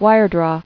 [wire·draw]